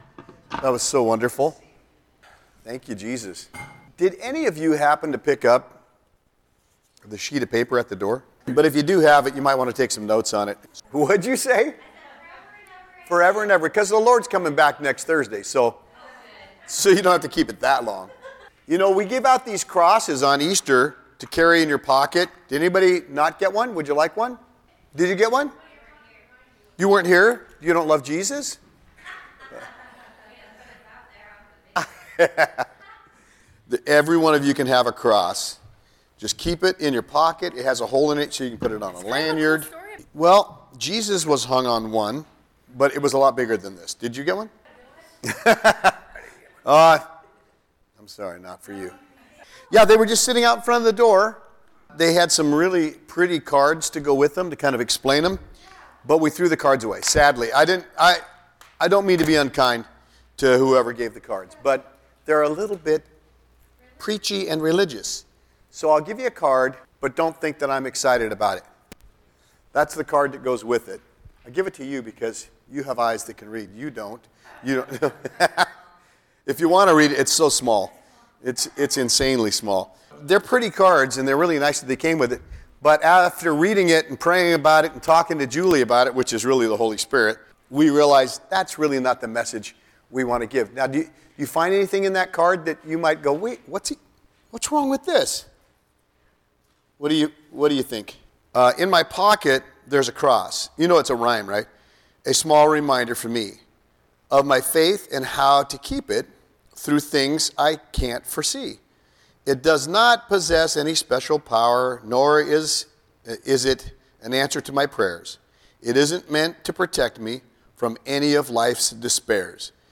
Topic: Your Identity in Christ All Sermons